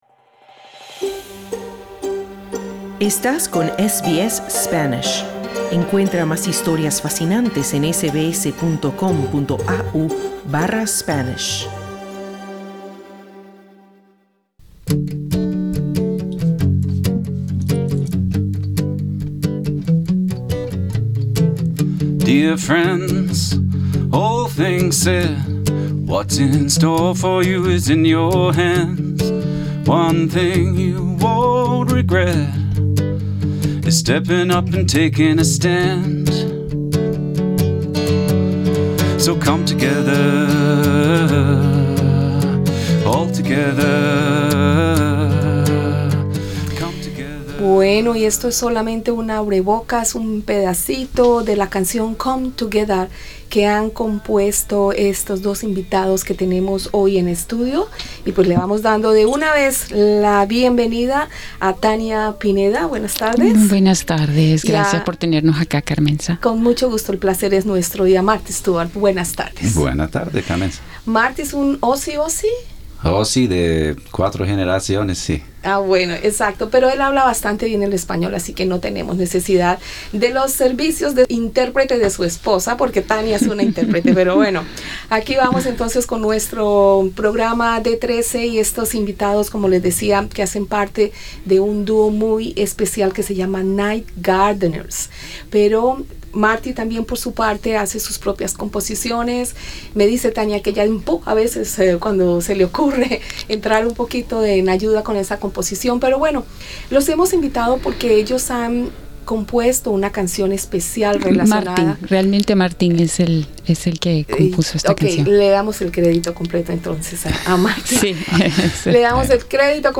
en los estudios de SBS en Melbourne